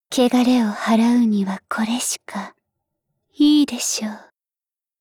Cv-970501_battlewarcry.mp3 （MP3音频文件，总共长5.0秒，码率322 kbps，文件大小：198 KB）